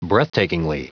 Prononciation du mot breathtakingly en anglais (fichier audio)
Prononciation du mot : breathtakingly